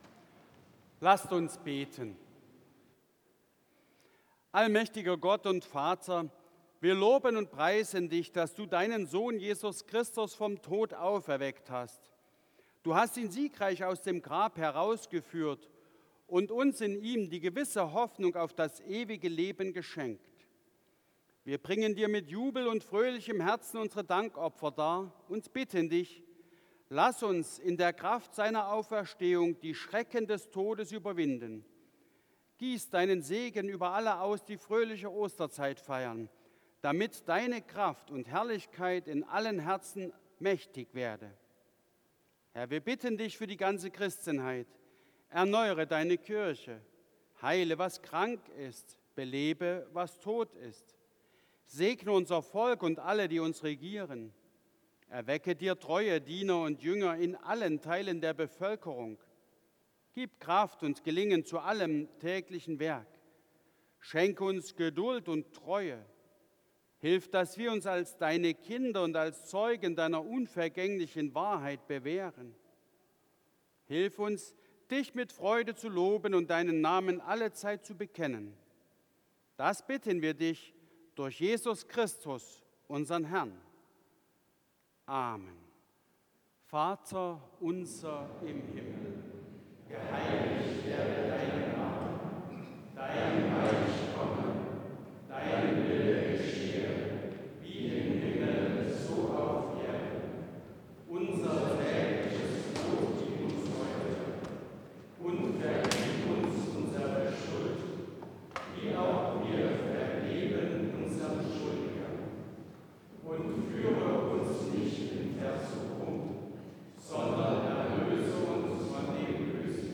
Gebet, Vaterunser, Entlassung und Segen Ev.-Luth.
Audiomitschnitt unseres Gottesdienstes vom 2. Sonntag nach Ostern 2025.